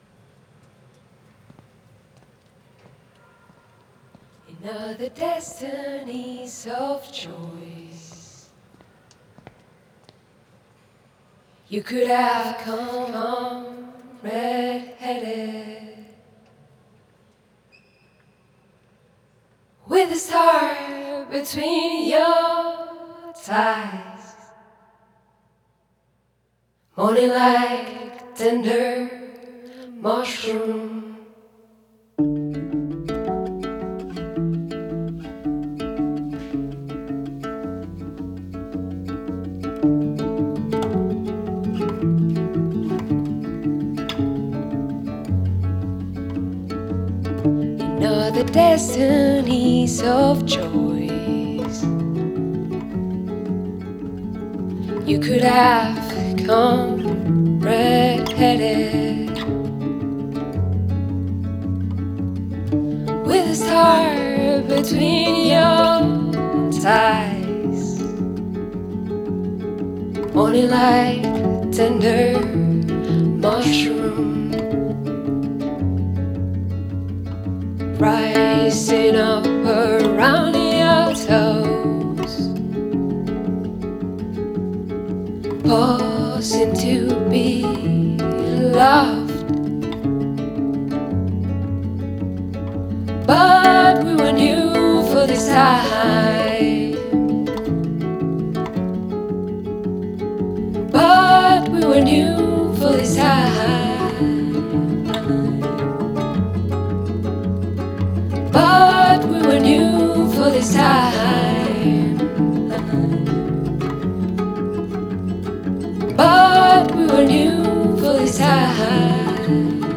Voz y guitarra acústica
Guitarra acústica
Grabado en Cholula, México, 2022.